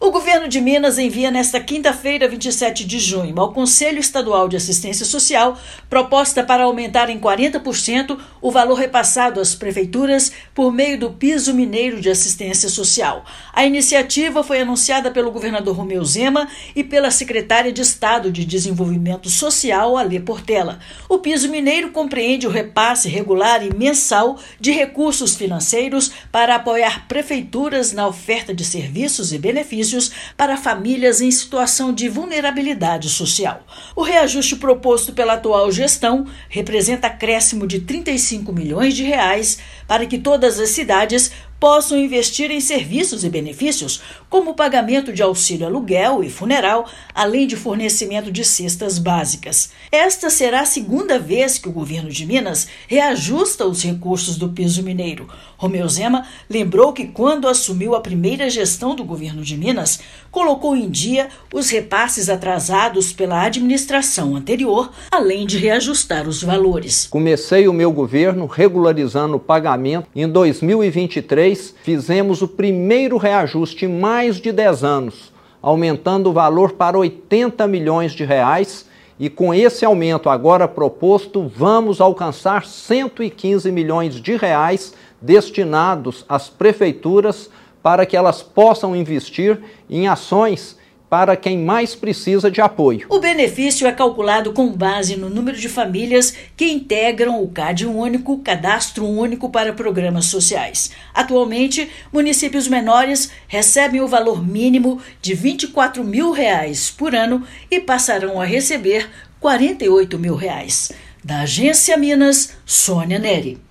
Reajuste representa um acréscimo de R$ 35 milhões para que os Municípios possam investir em ações para a população em vulnerabilidade social. Ouça matéria de rádio.